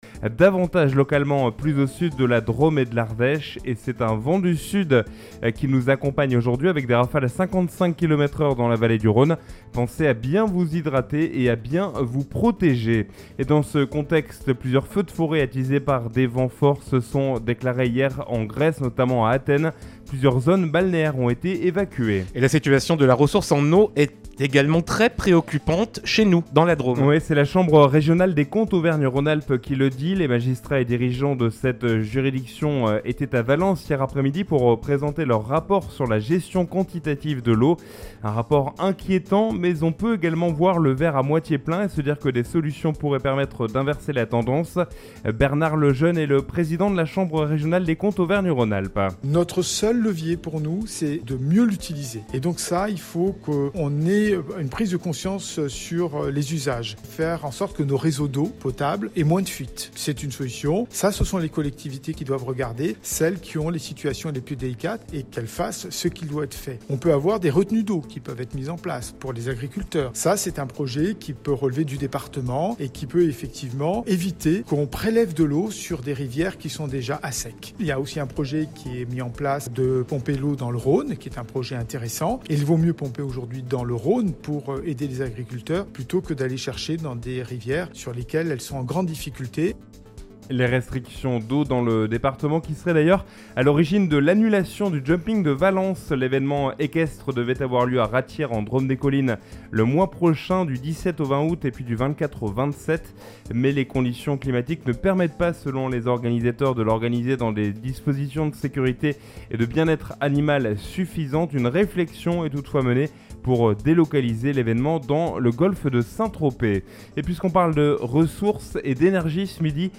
Mardi 18 juillet 2023 : Le journal de 12h